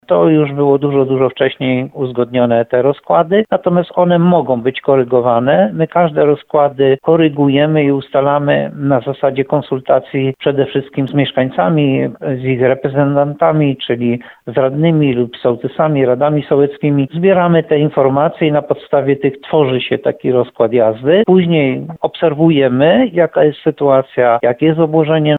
– mówi Jan Golba burmistrz Muszyny.